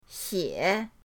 xie3.mp3